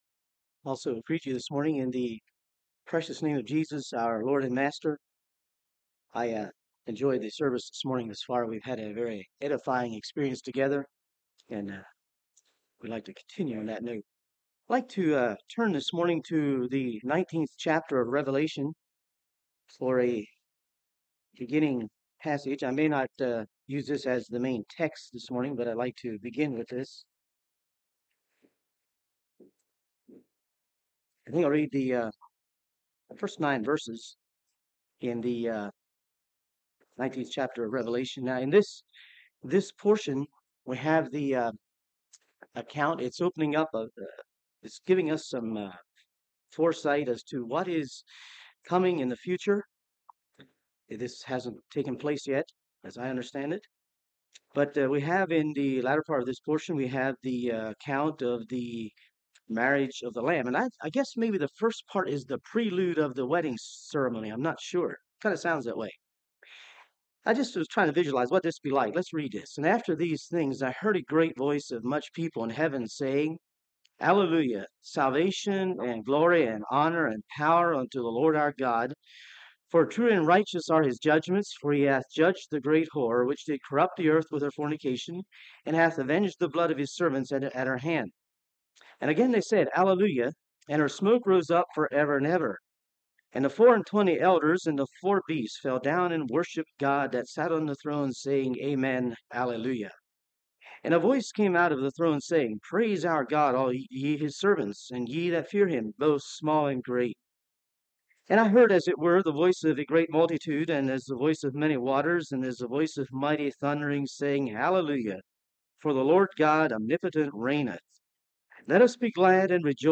Service Type: Sunday Morning Topics: Bride of Christ , The Church « Unity